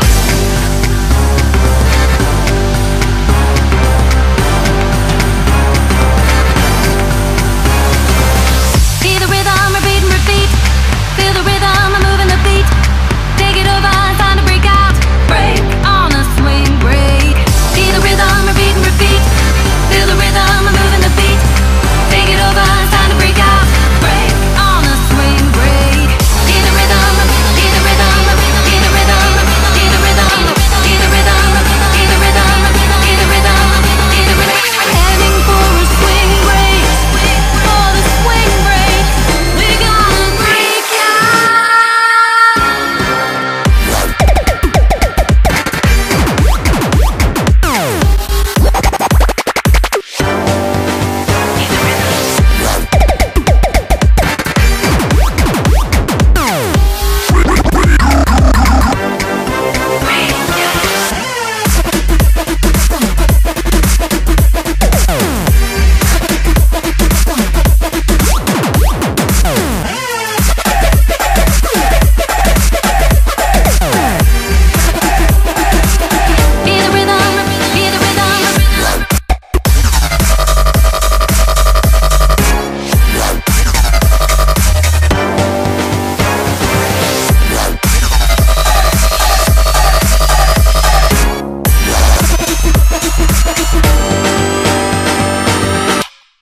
BPM220